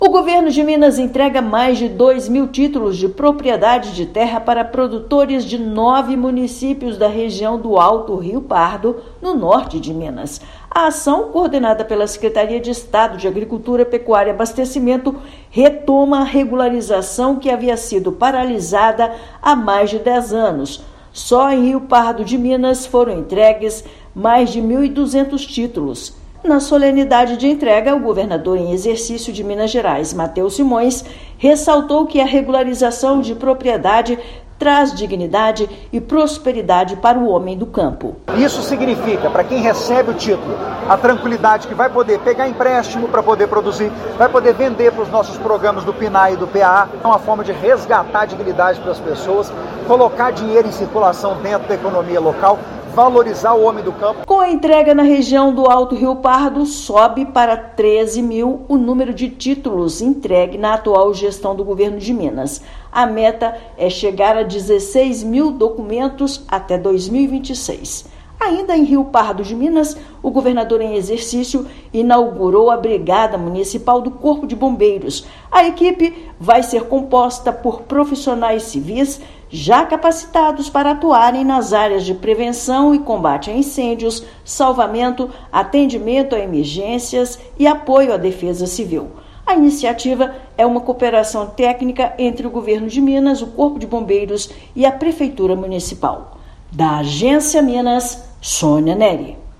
Acordos com Ministério Público e Tribunal de Justiça encerram 14 anos de espera para proprietários de nove municípios da região. Ouça matéria de rádio.